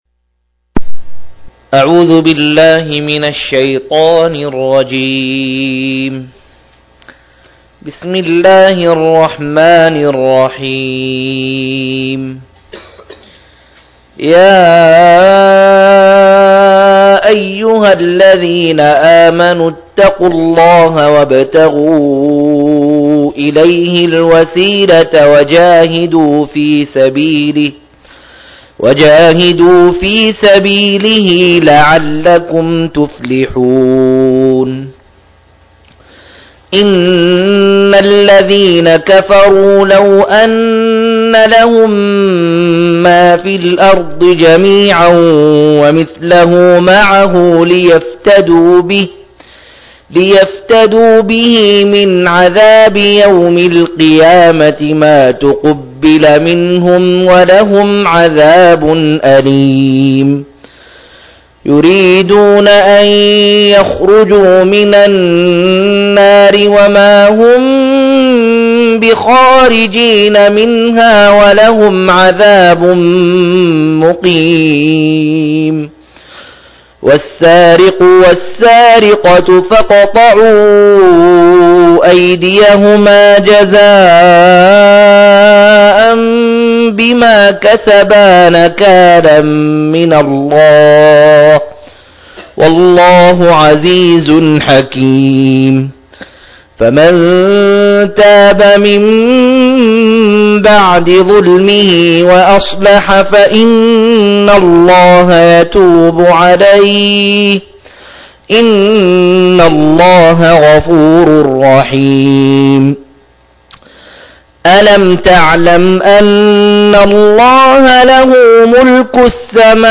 115- عمدة التفسير عن الحافظ ابن كثير رحمه الله للعلامة أحمد شاكر رحمه الله – قراءة وتعليق –